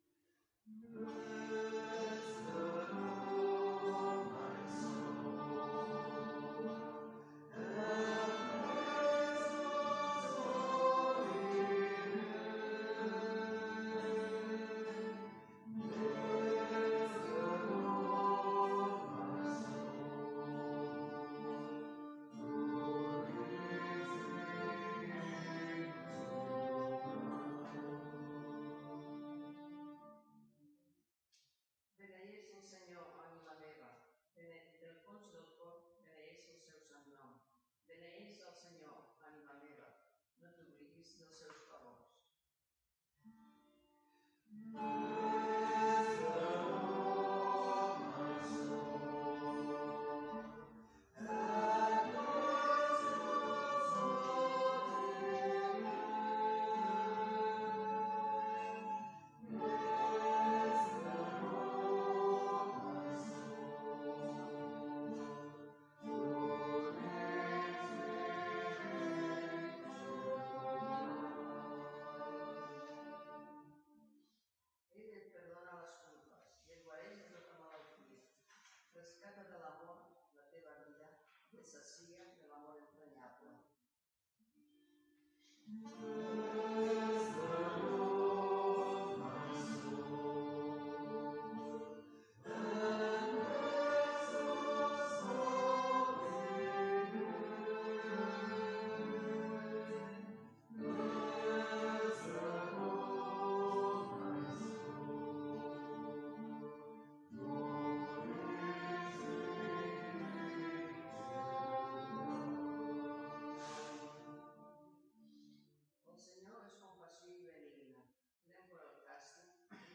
Pregària de Taizé a Mataró... des de febrer de 2001
Parròquia de la Mare de Déu de Montserrat - Diumenge 23 de febrer de 2020